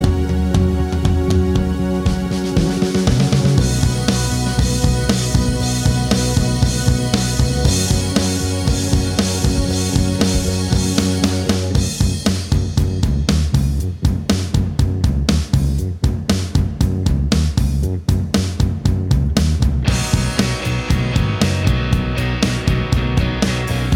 No Lead Guitar Pop (2010s) 5:01 Buy £1.50